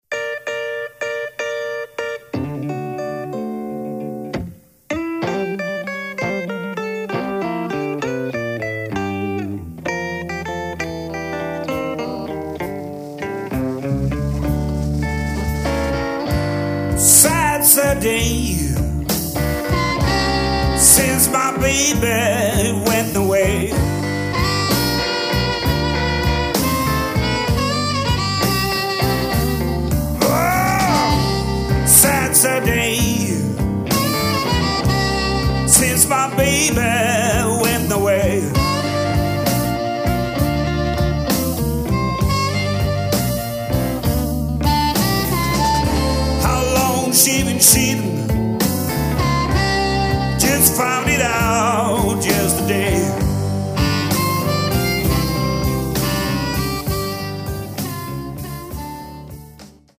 Sång/Gitarr
Munspel
Trummor